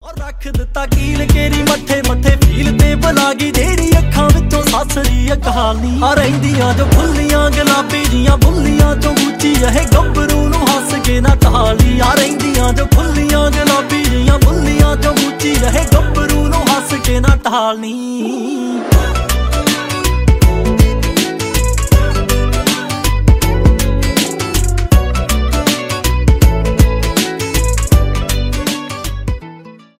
поп
рэп